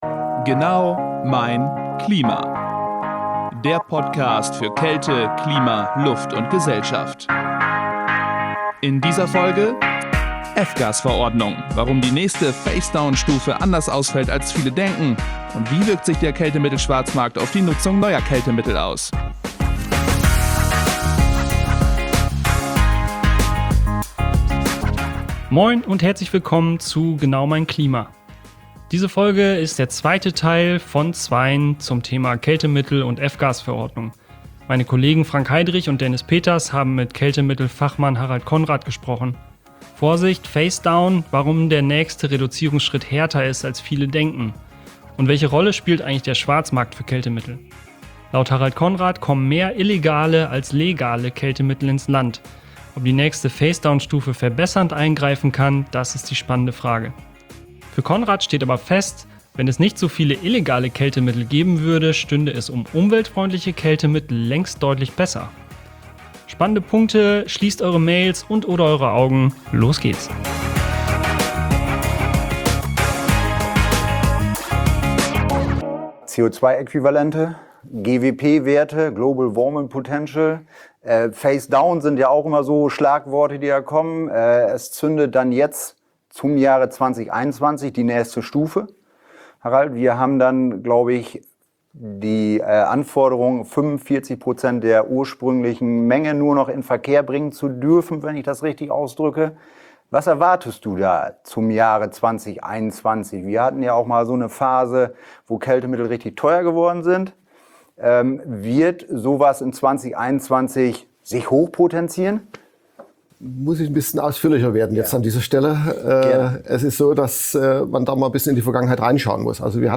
Wechselnde Interviewpartner plauschen und fachsimpeln in lockerer Runde.